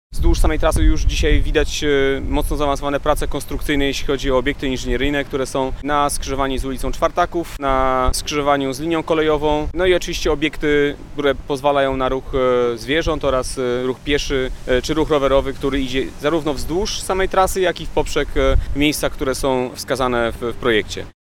O znaczeniu inwestycji mówi wiceprezydent stolicy Michał Olszewski.